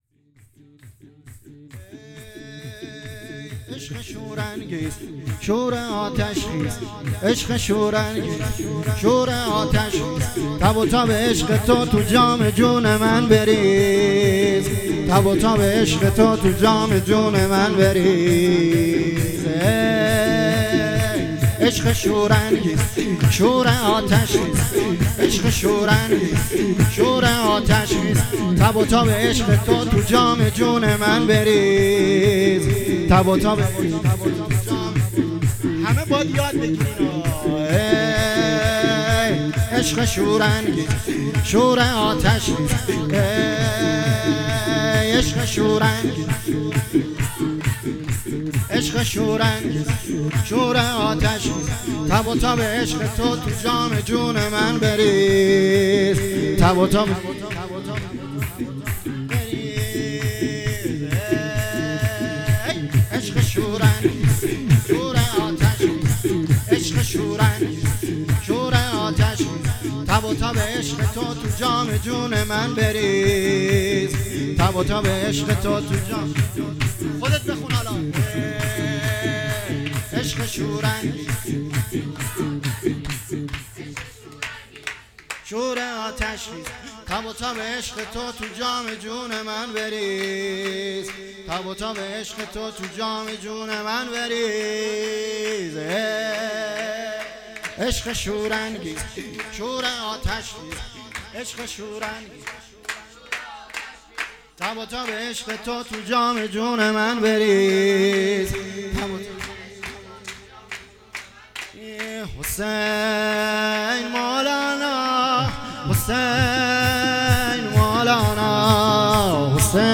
سرود شور
مراسم ولادت سرداران کربلا